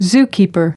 15. zookeeper /ˈzuːkiːpər/: người trông coi sở thú